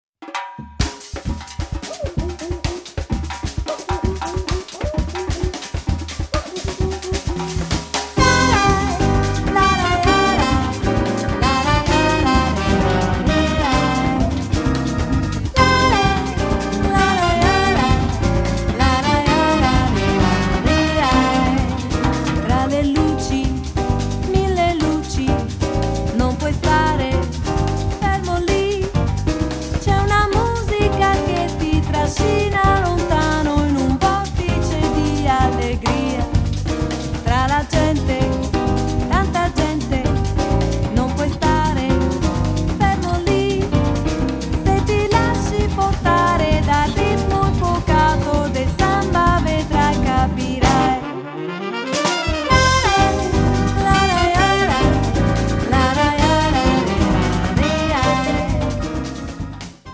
basso
batteria
percussioni
sax contralto
Il ritmo della bossa sottende tutti i brani
così limpida e dolce
un samba "tirato" e brioso